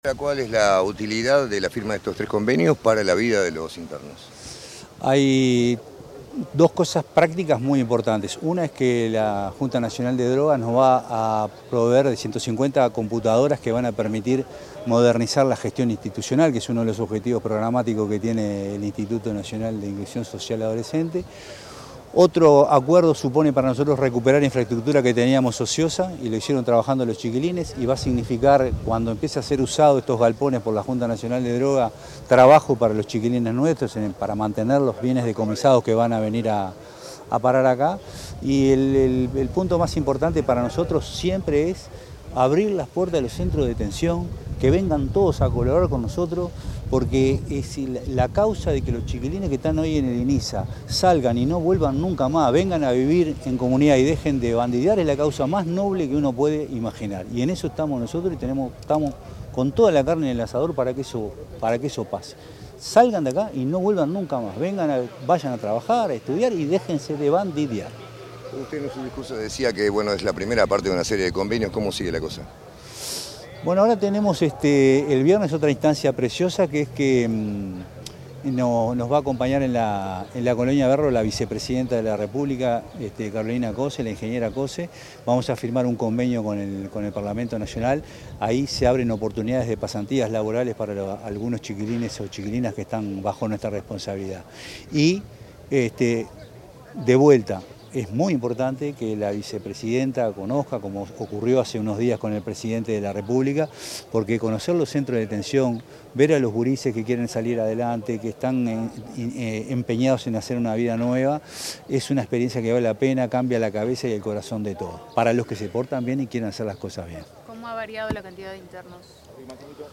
Declaraciones del presidente del Inisa, Jaime Saavedra
Declaraciones del presidente del Inisa, Jaime Saavedra 11/11/2025 Compartir Facebook X Copiar enlace WhatsApp LinkedIn Tras la firma de un acuerdo entre el Instituto Nacional de Inclusión Social Adolescente (Inisa) y la Junta Nacional de Drogas (JND), el presidente del Inisa, Jaime Saavedra, dialogó con los medios de prensa.